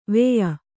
♪ veya